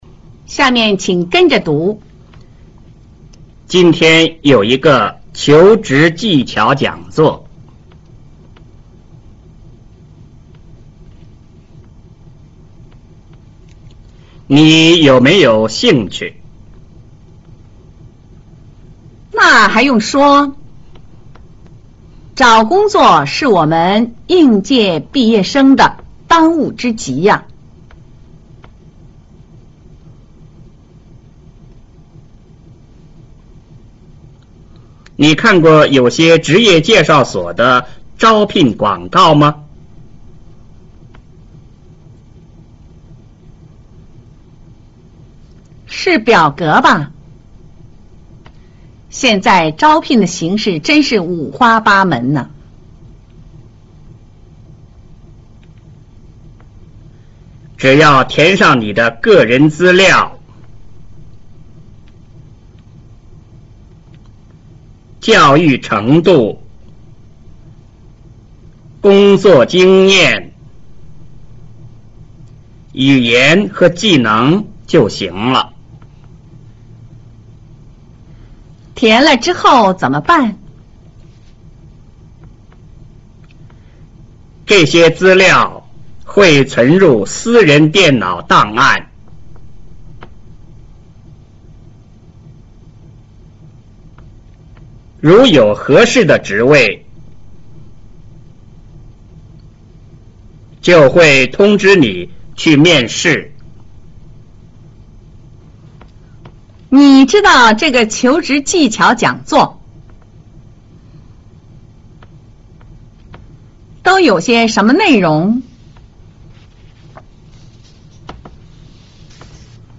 三、會話